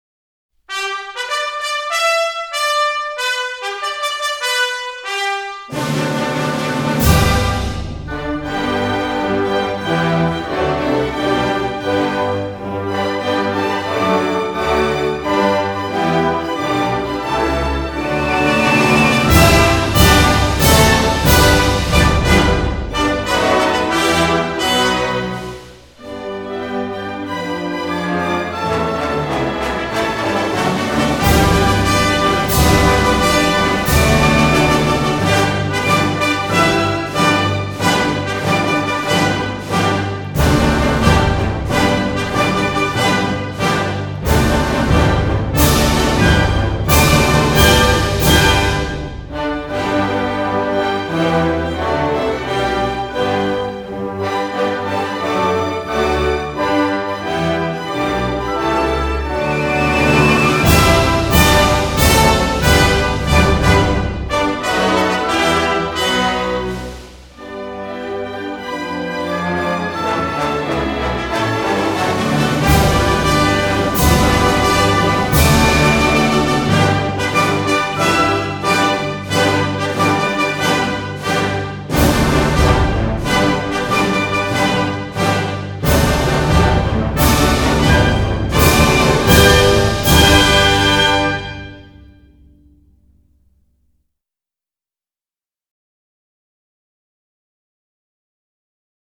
●录音制式：DDD STEREO
在演绎方面无论是乐队、合唱团还是独唱演员都表现出很高的艺术水准，演奏、演唱都充满着热情和力量。
虽然录制水准与顶尖的发烧录音还有一定的差距，但各个频段的细节表现以及整体平衡感已经足够令人满意。